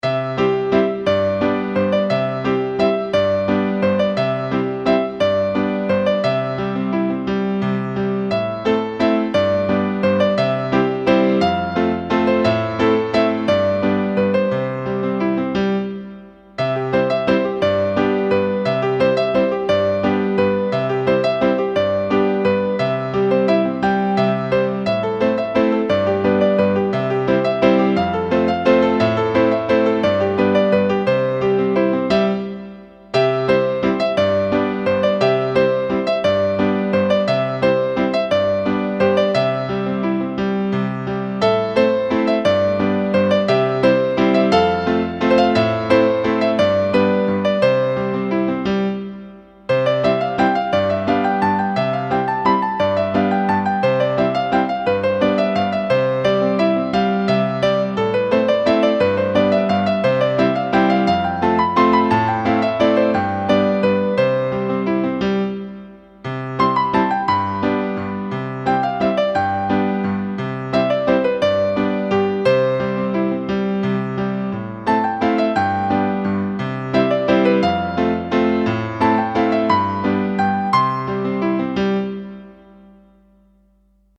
Piano vals
vals piano música